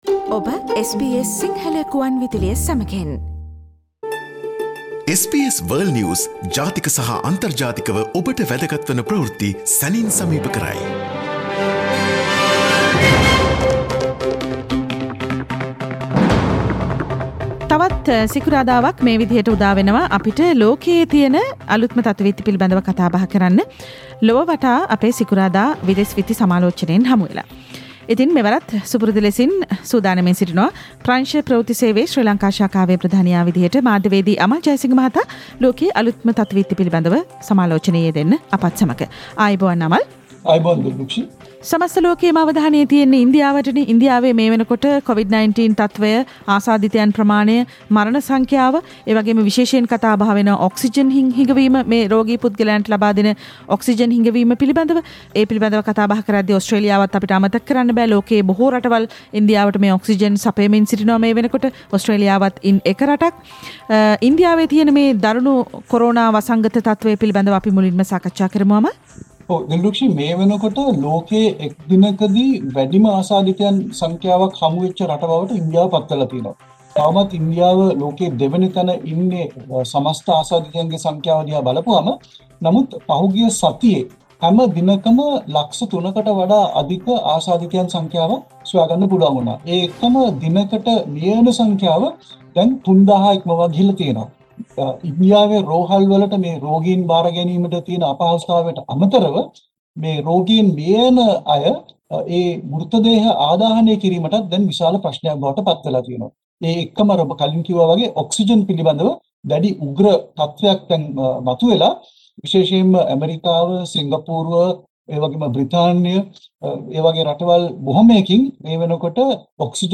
Listen to the latest news from around the world this week from our weekly "Around the World" foreign news review.